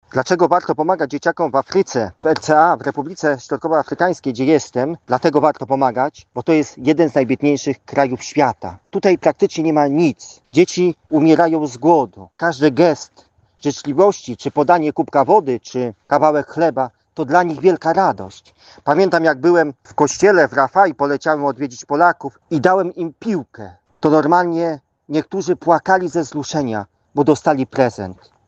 W rozmowie z Polskim Radiem Rzeszów zwrócił on uwagę na to, że istotna jest każda forma wsparcia.